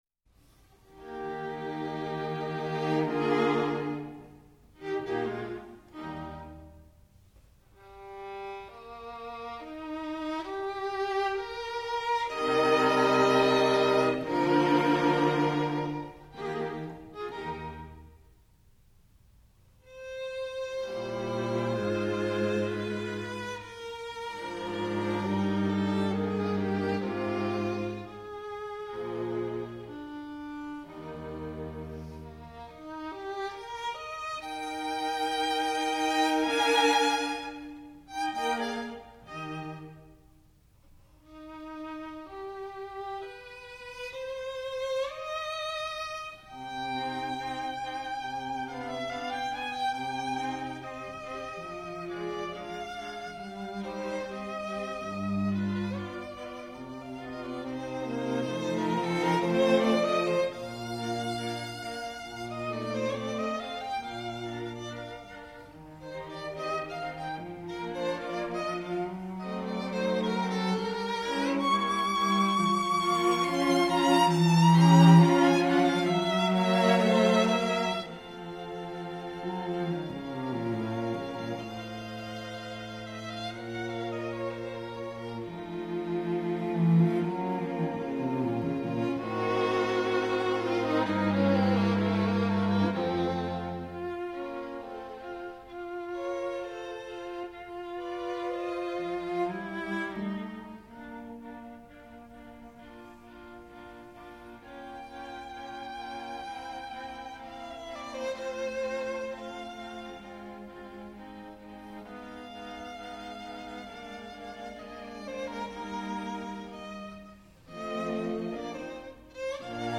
String Quartet in B flat major "The Hunt"
Adagio